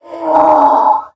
1.21.5 / assets / minecraft / sounds / mob / horse / donkey / angry1.ogg
angry1.ogg